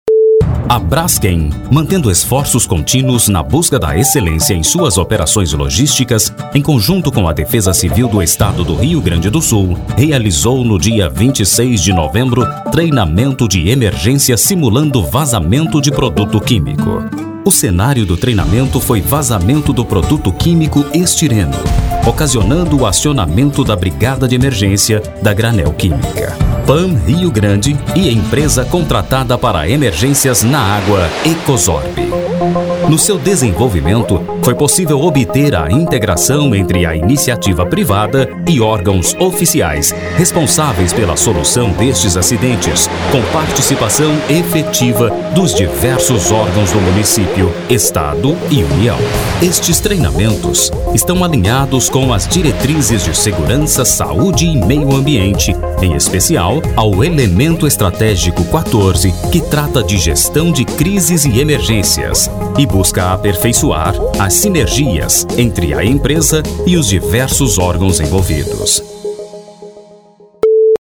• documentário